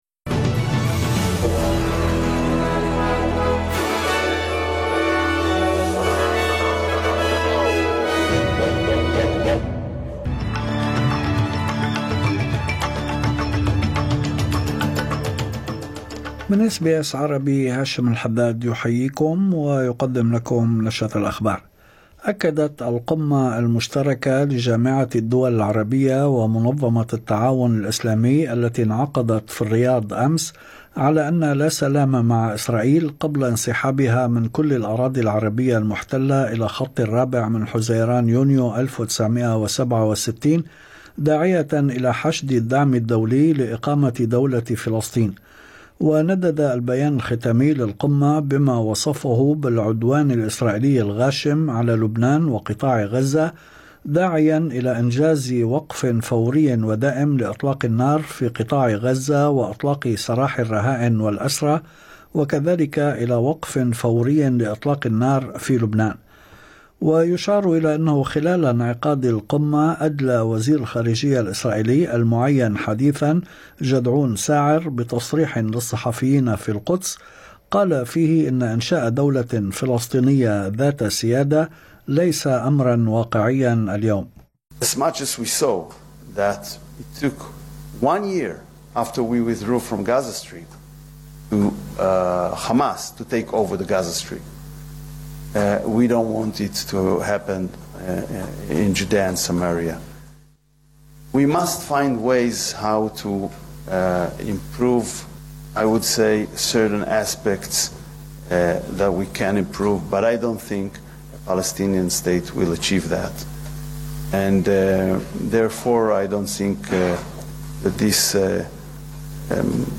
نشرة أخبار الظهيرة 12/11/2024